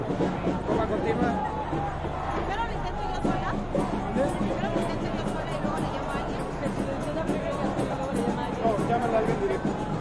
描述：1968年，为了纪念被杀害的学生，墨西哥的一群暴徒......街道，人群，学生，人，墨西哥，西班牙语的一切
Tag: 人群 抗议 暴民